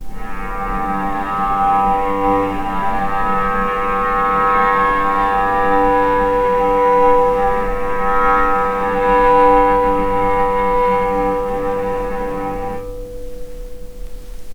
vc_sp-G2-pp.AIF